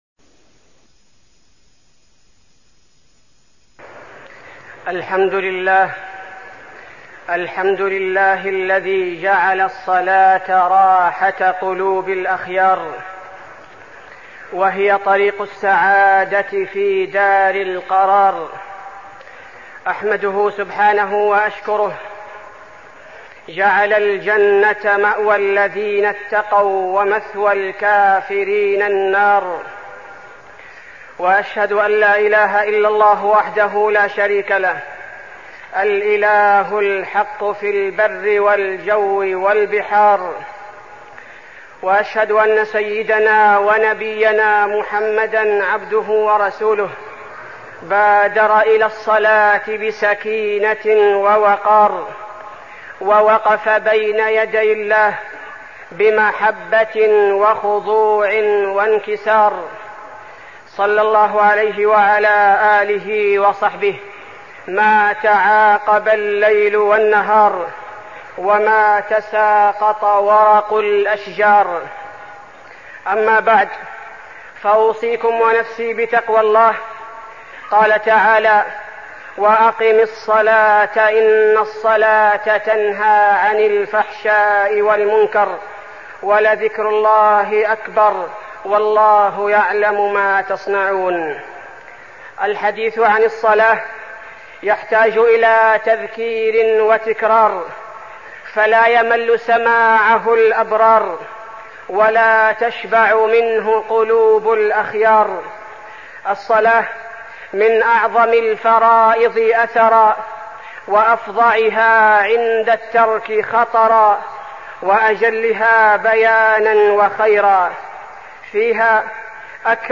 تاريخ النشر ١٠ جمادى الآخرة ١٤١٦ هـ المكان: المسجد النبوي الشيخ: فضيلة الشيخ عبدالباري الثبيتي فضيلة الشيخ عبدالباري الثبيتي الصلاة